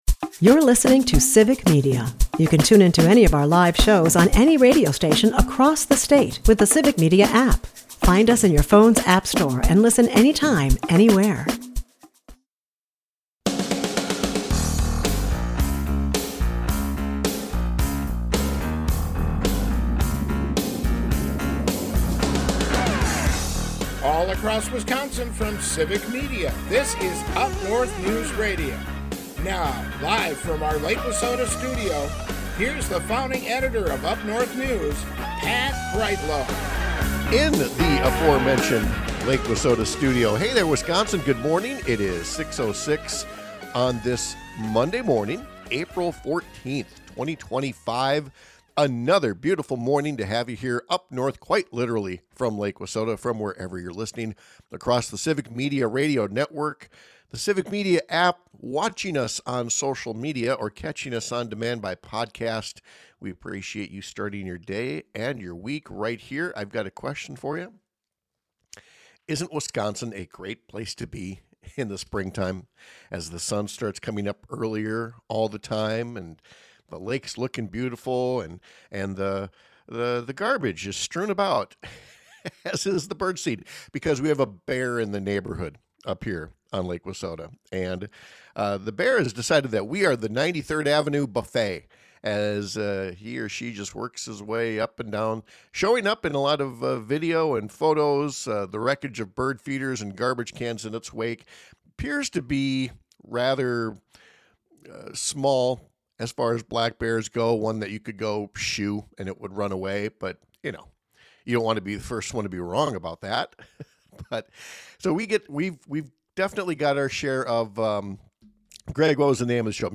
They take calls from the listeners and read texts, as well who very happy to share their thoughts with the gang about Tuesday night.